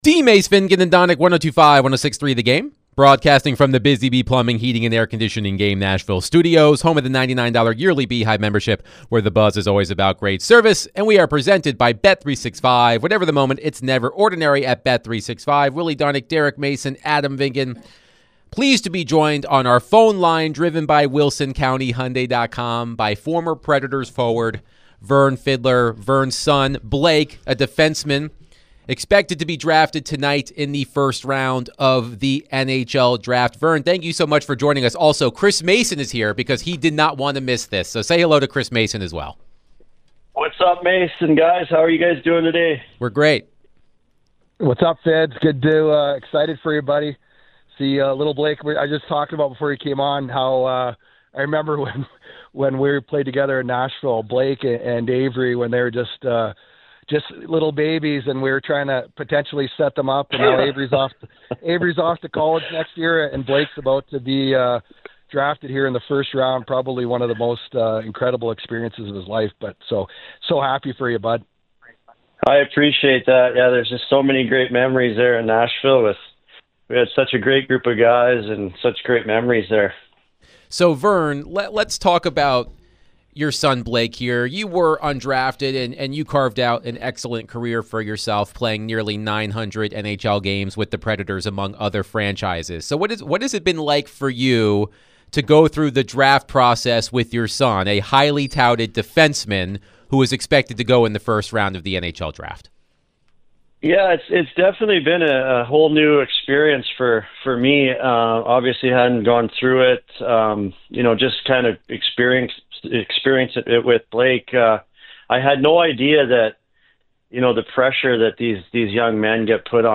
The guys chatted with former Nashville Predator Vern Fiddler.